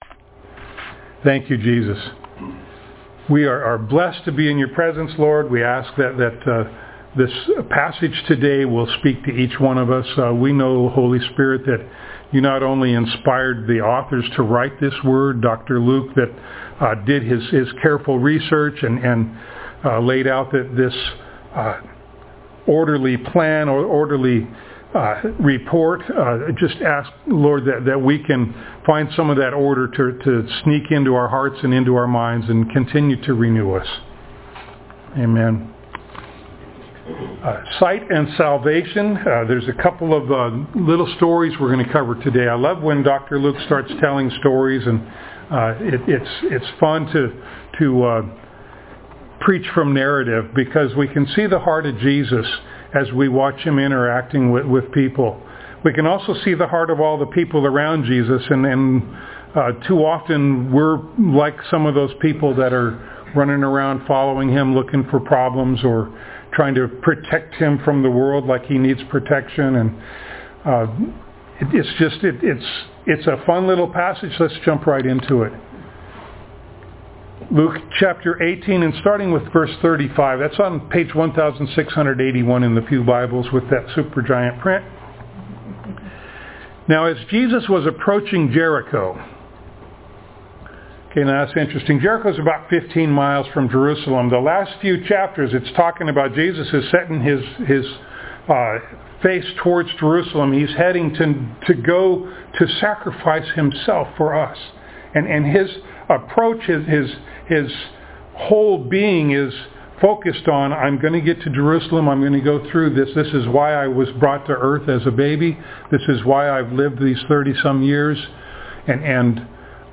Passage: Luke 18:35-19:10 Service Type: Sunday Morning